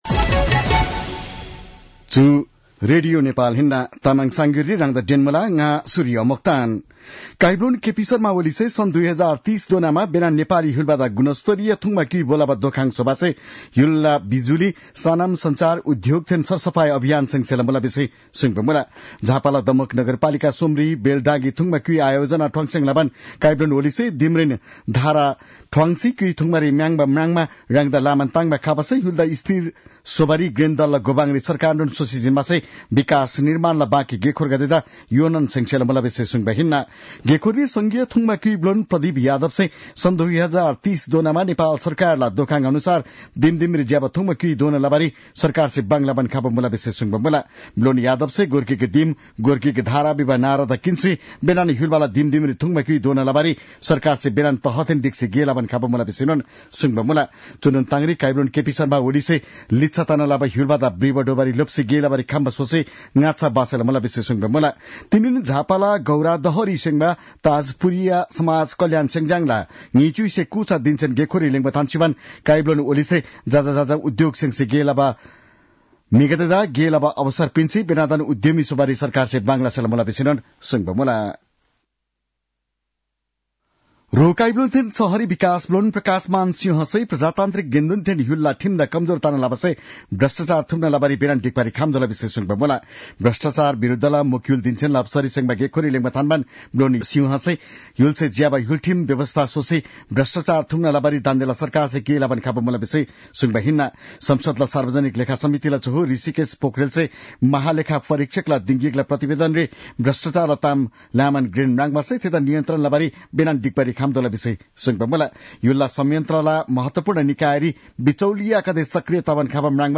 तामाङ भाषाको समाचार : २५ मंसिर , २०८१
Tamang-news-8-24.mp3